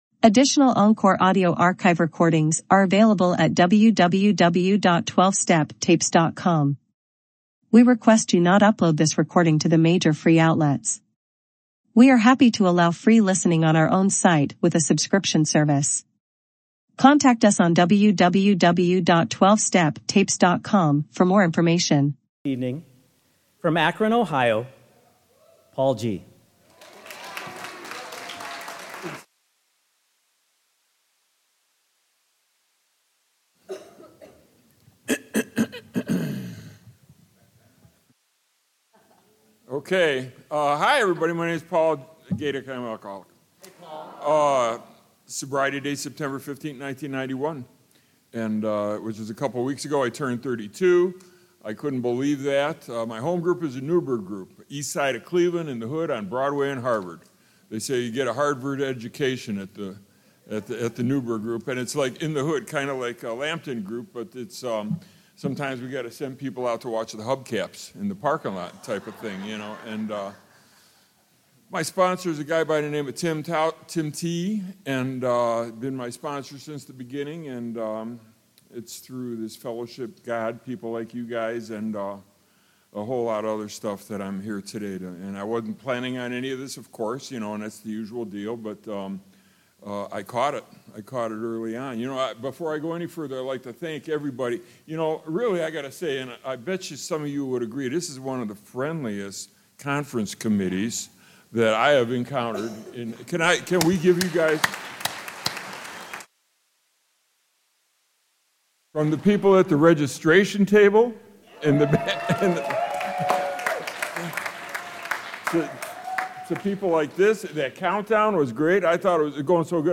50th Annual Antelope Valley Roundup